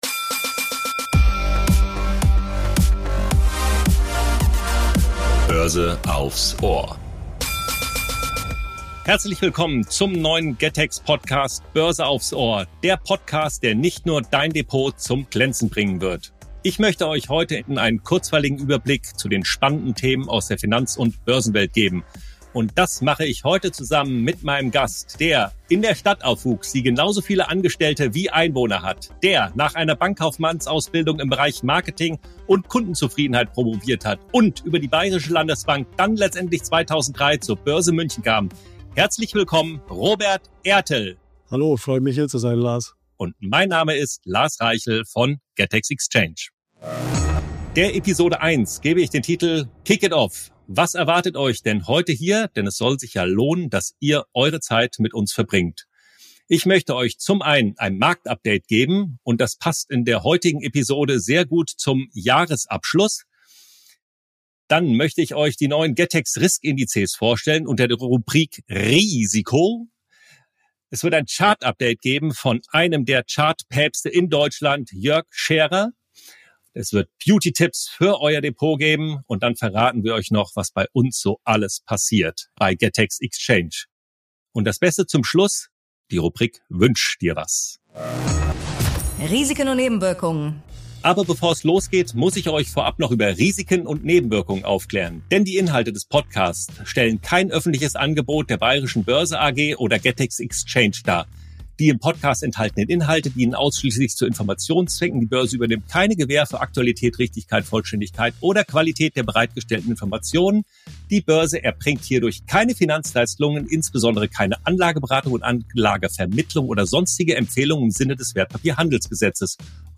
Aus der Hopfenpost in München